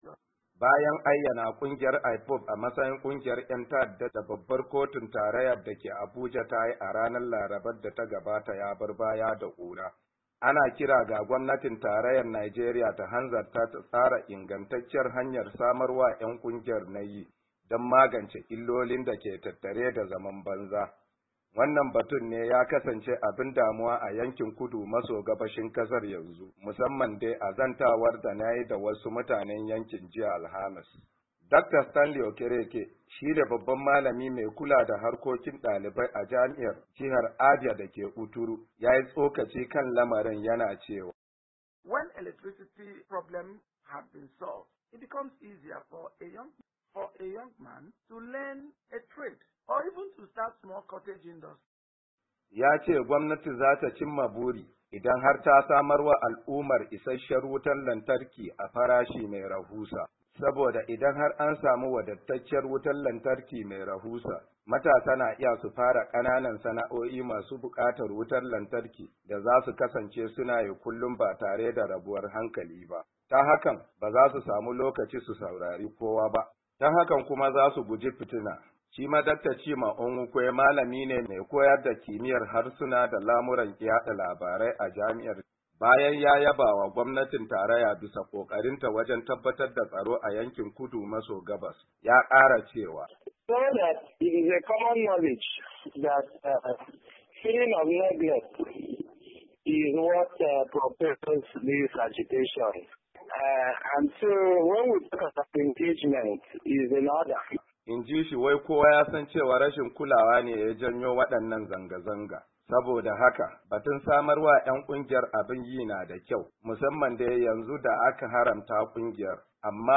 Yayin da Muryar Amurka ta zanta da wasu mazauna yankin Kudu maso Gabashin Najeriya, da akwai alamar cewa batun rashin aikin yi shine ya mamaye yankin ya kuma haddasa damuwa ga al’ummar.